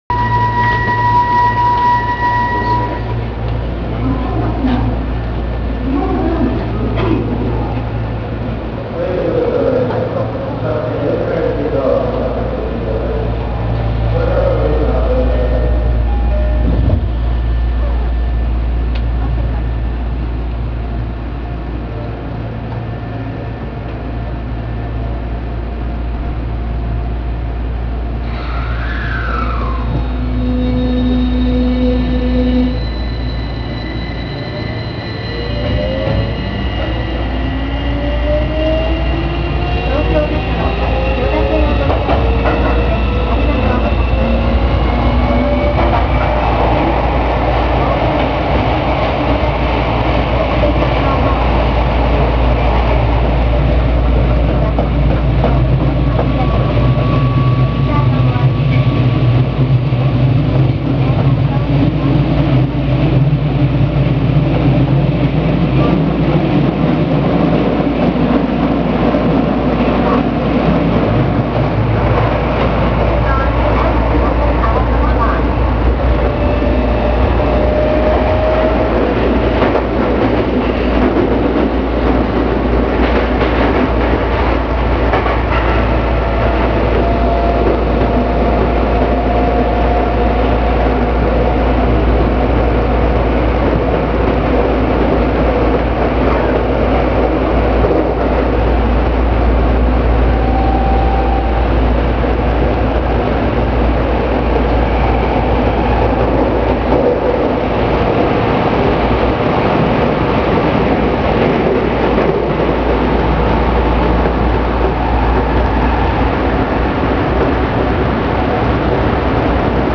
・16000系2次車まで走行音
【千代田線】北千住〜町屋（3分20秒：1.06MB）
今までの車両に無かった走行音が目立っています。